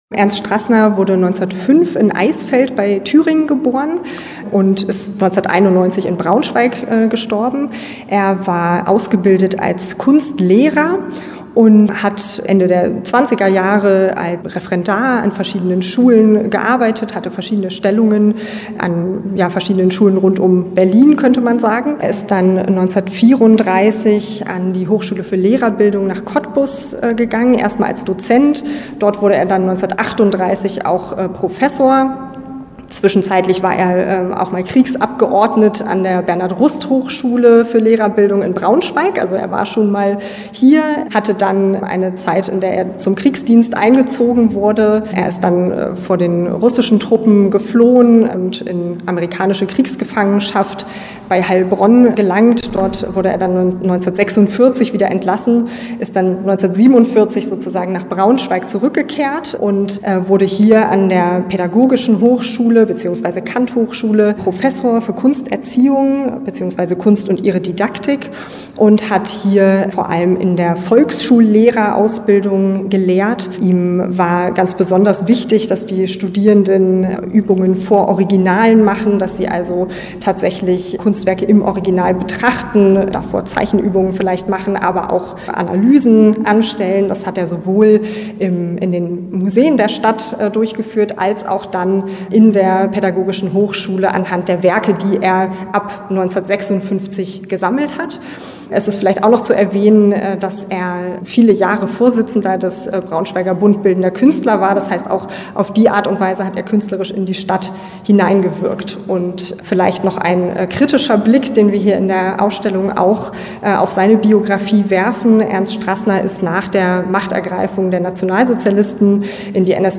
Interview-Ausgehoben.mp3